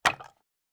Wood Impact 4_4.wav